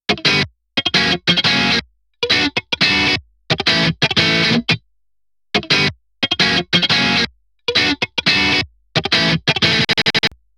エレキギターサウンド試聴